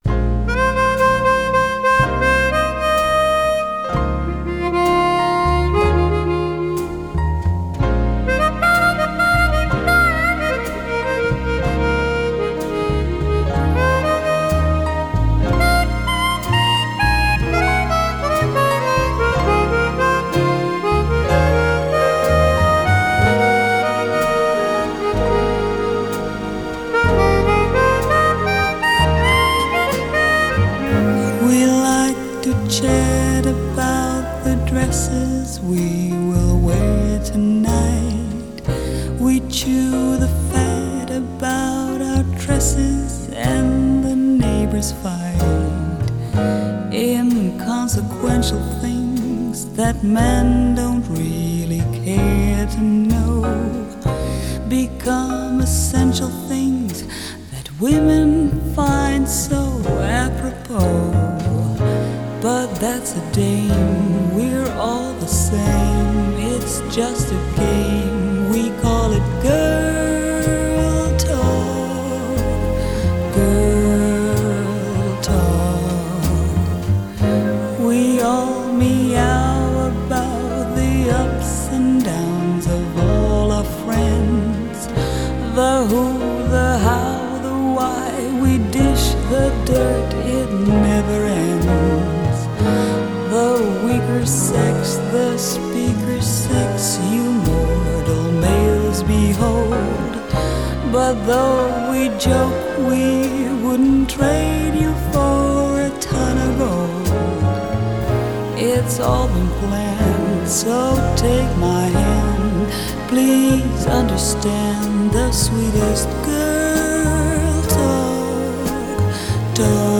Genre: Jazz
Harmonica